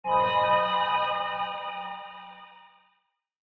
cave1.mp3